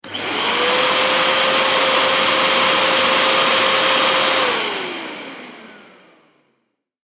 掃除機長-1.mp3